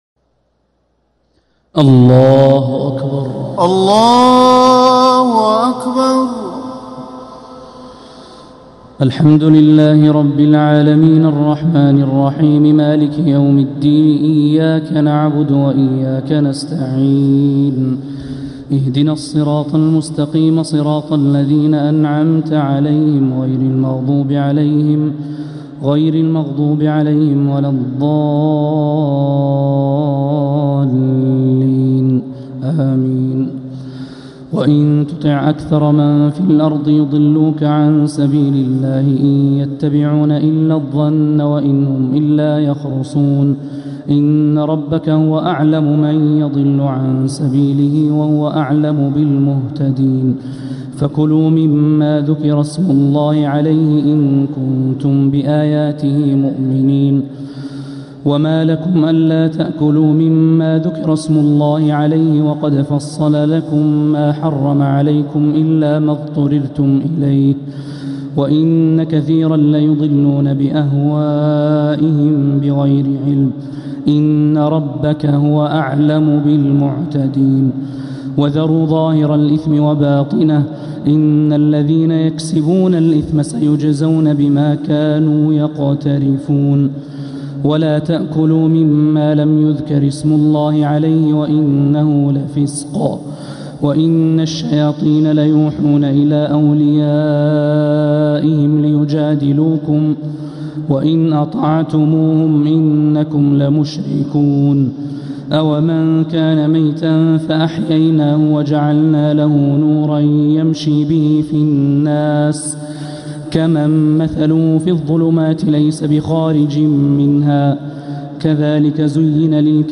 تراويح ليلة 10 رمضان 1447هـ من سورة الأنعام (116-150) | Taraweeh 10th niqht Ramadan Surat Al-Anaam 1447H > تراويح الحرم المكي عام 1447 🕋 > التراويح - تلاوات الحرمين